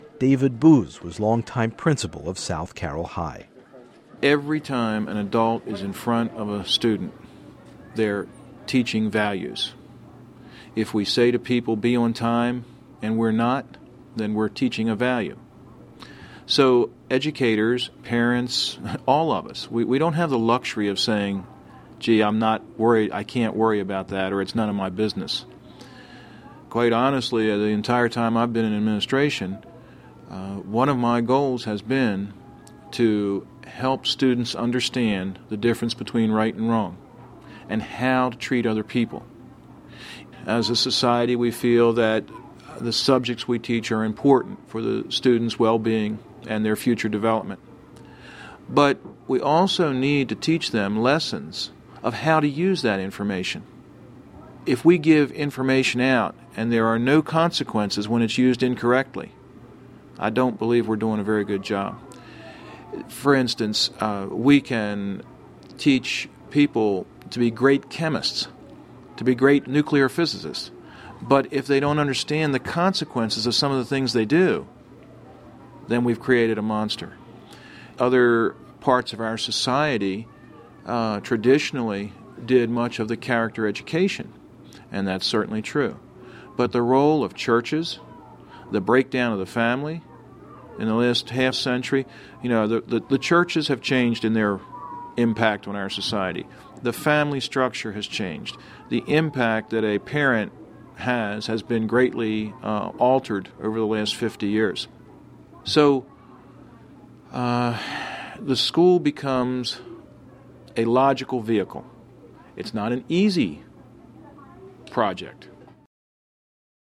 In Part Two of Humankind’s look at South Carroll high school, four mothers describe what they see as essential to educating America’s students today.